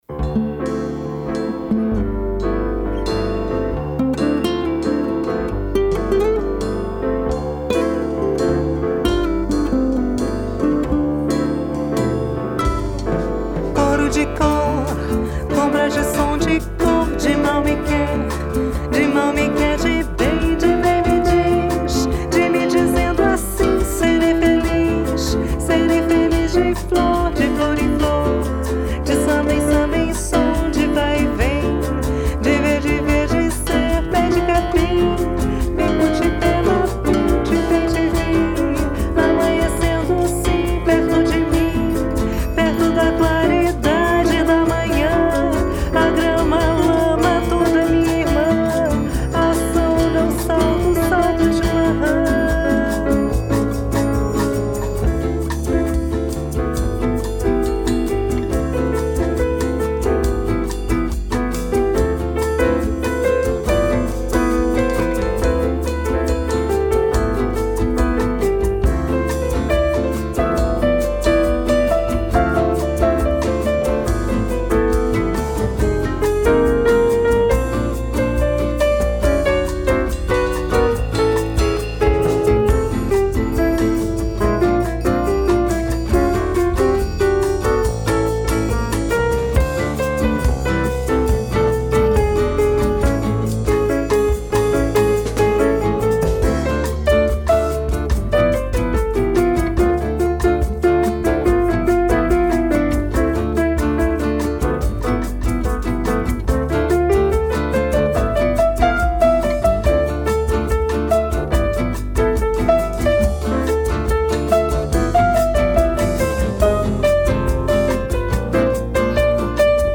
bossa
This is traditional Brazilian music in Portuguese ( sounds more than a little like Klingon to me!)